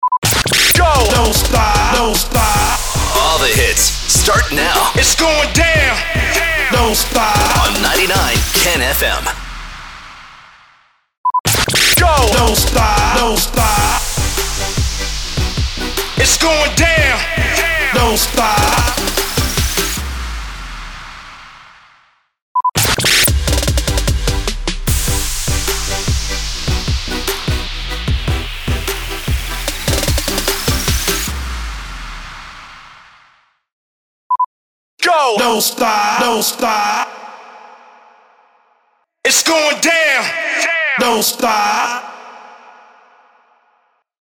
333 – SWEEPER – REJOIN – ALL THE HITS START NOW
333-SWEEPER-REJOIN-ALL-THE-HITS-START-NOW.mp3